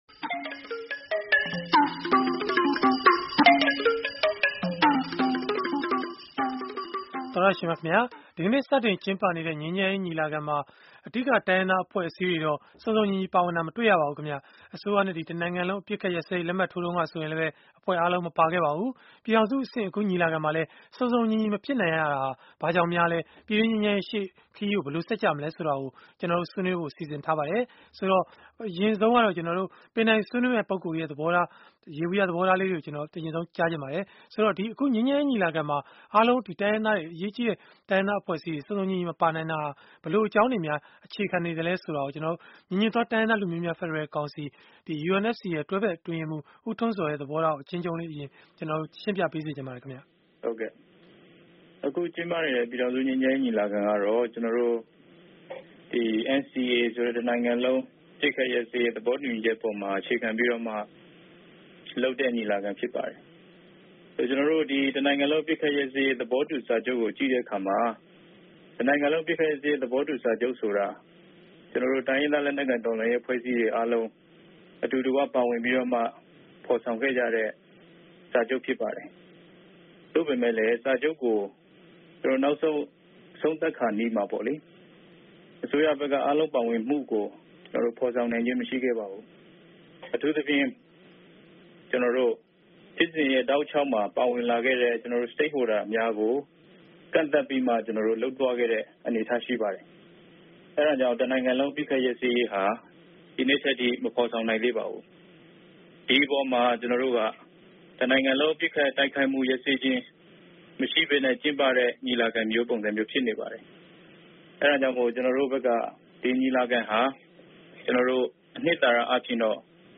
တိုင်းရင်းသား အဖွဲ့အစည်းတွေ စုံစုံညီညီမပါတဲ့ ငြိမ်းချမ်းရေး ညီလာခံကနေ ပြည်တွင်းငြိမ်းချမ်းရေးအတွက် ဘာတွေ မျှော်လင့်လို့ ရနိုင်သလဲ၊ အစိုးရသစ် လက်ထက်မှာရော မျှော်လင့်စရာ အလားအလာ ဘာတွေရှိနိုင်မလဲ ဆိုတာကို အင်္ဂါနေ့ တိုက်ရိုက်လေလှိုင်း အစီအစဉ်မှာ ဆွေးနွေးထားပါတယ်။